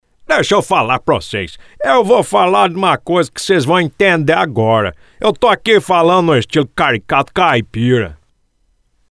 CARICATO CAIPIRA: